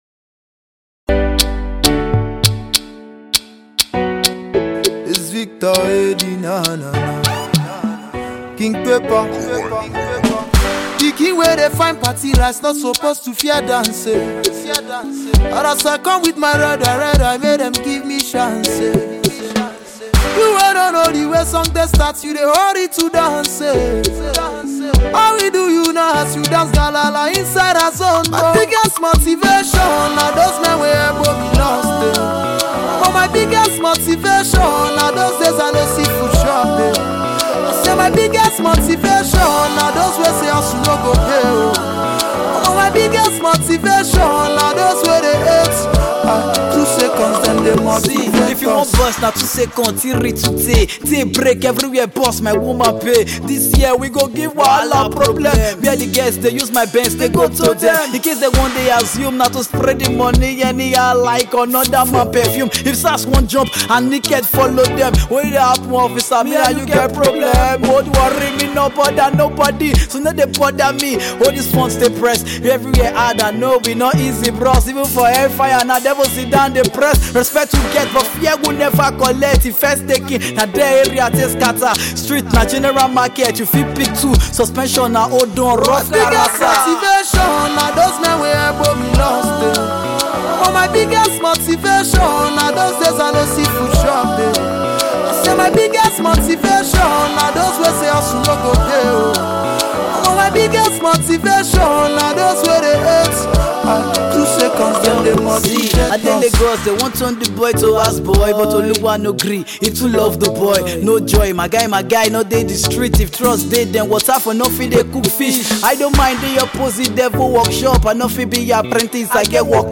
South-South rapper